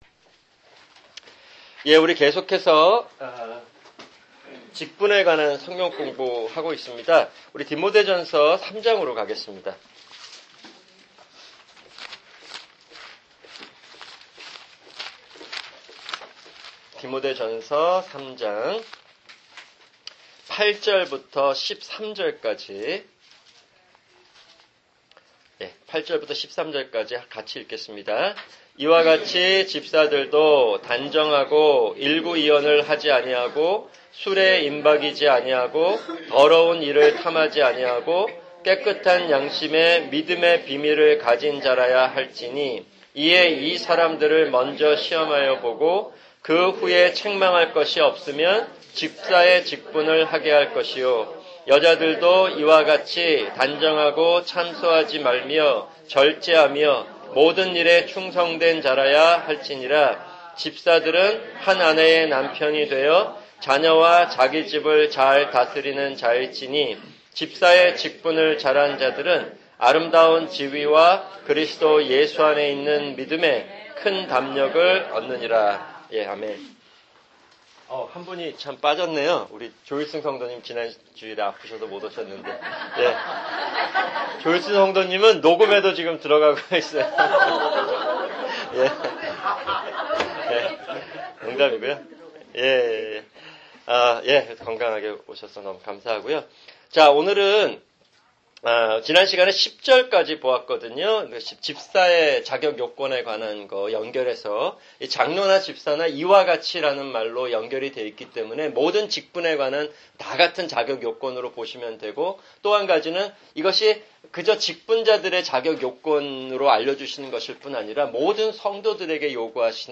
[주일 성경공부] 직분(8)